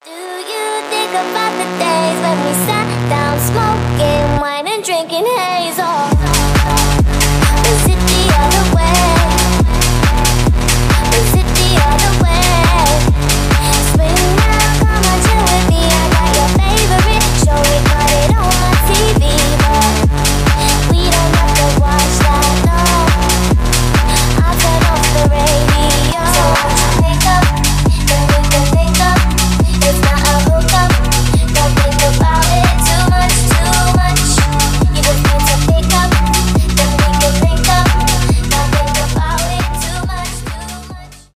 garage house
dance pop , electronic